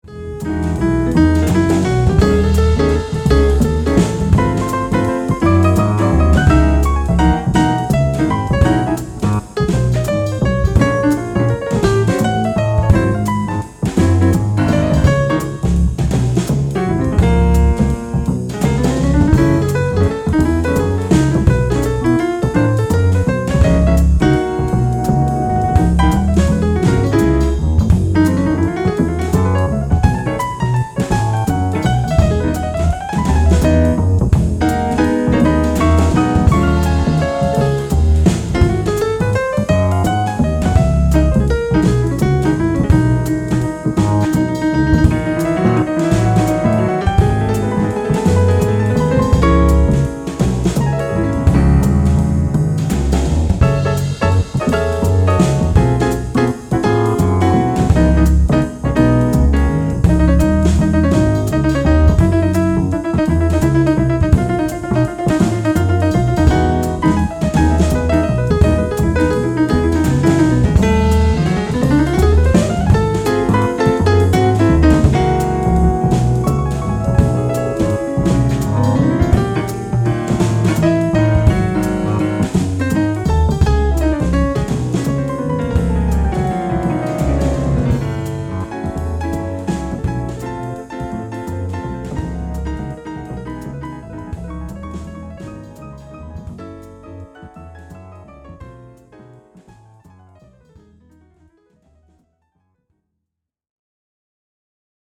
Demo-Bosendorfer.mp3